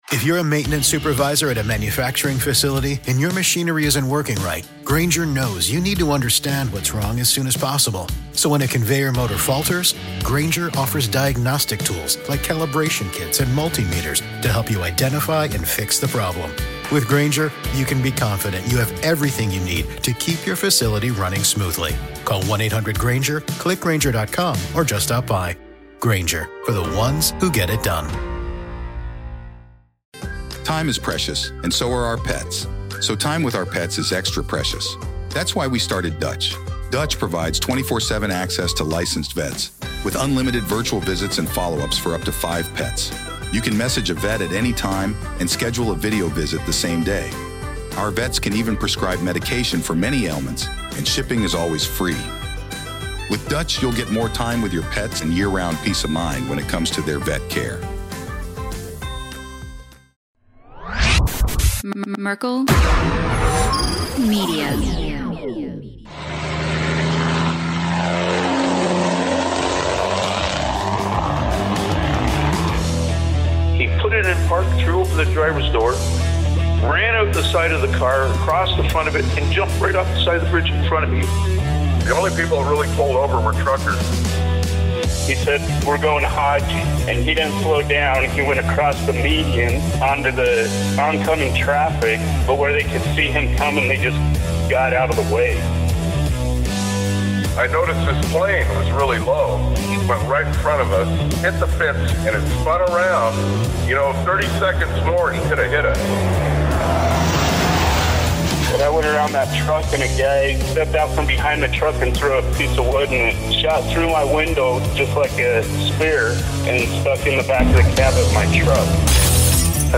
The couple shares humorous and heartfelt stories about marriage, raising kids, and navigating health issues. Topics range from driving school buses and dealing with unruly kids to earning a Class A license and the quirks of delivering milk. The episode is filled with laughter, unexpected turns, and touching moments, making for an entertaining and lighthearted discussion.